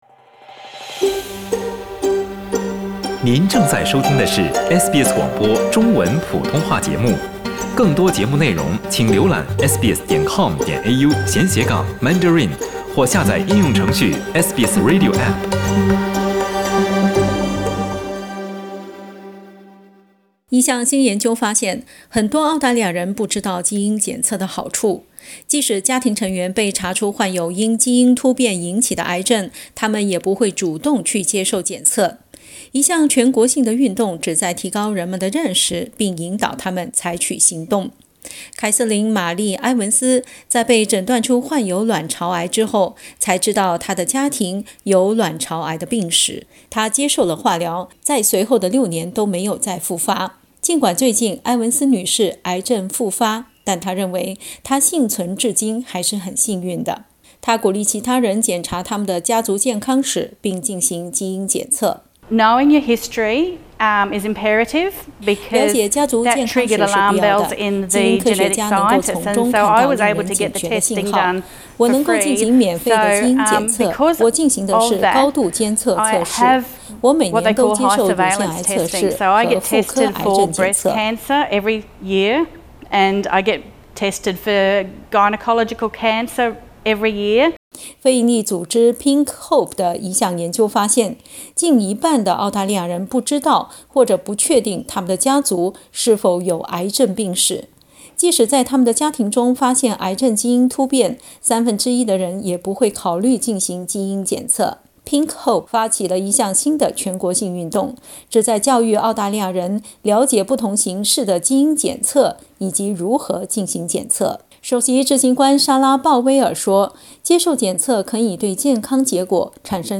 （點擊圖片收聽報道）